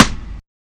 Snares
SNARE II.wav